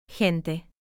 The hard G sound occurs when G is followed by the vowels A, O, or U. It sounds just like the G in English words like “go” or “game.”
Click the audio clip below to hear the hard G pronunciation in the word “Gato”.